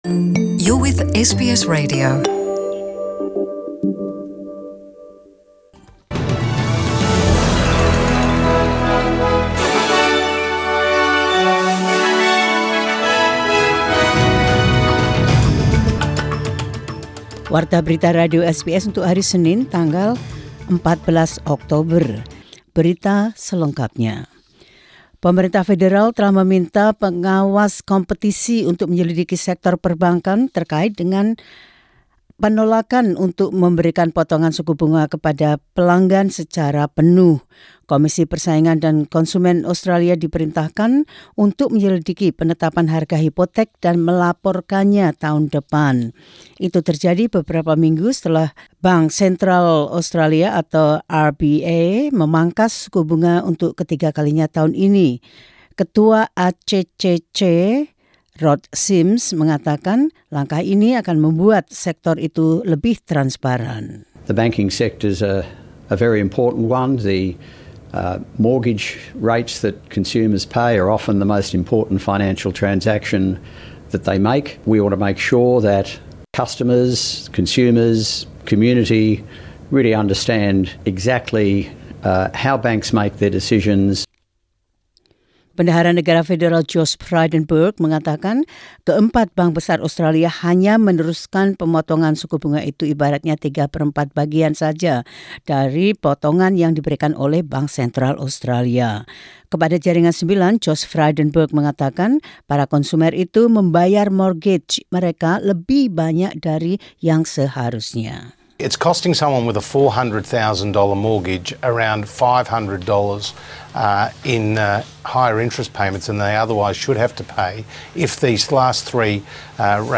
Radio SBS News in Indonesian 14 Oct 2019.